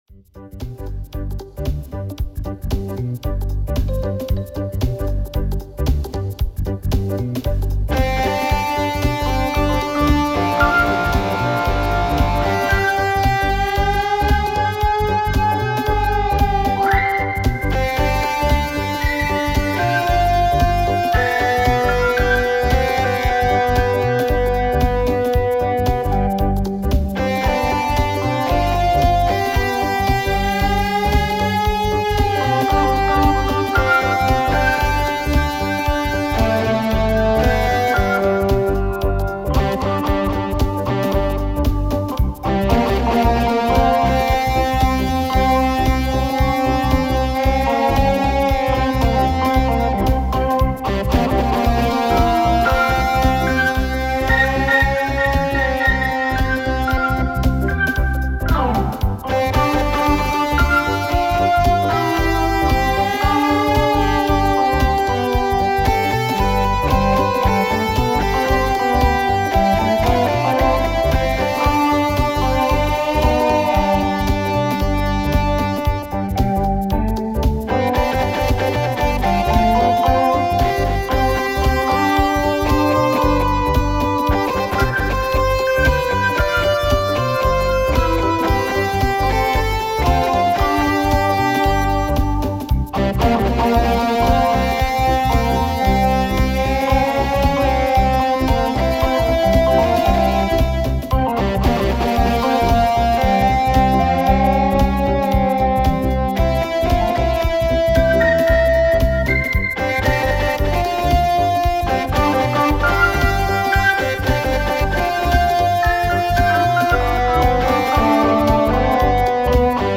Tempo: 110 bpm / Datum: 26.02.2017